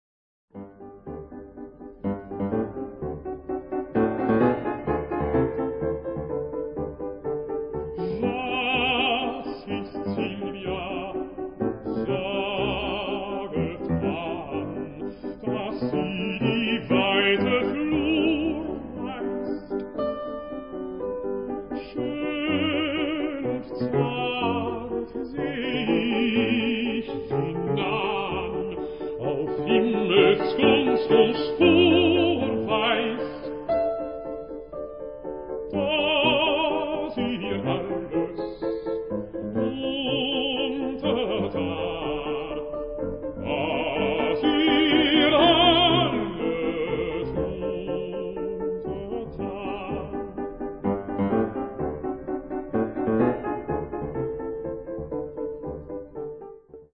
ein Melodiestück
Bariton
Piano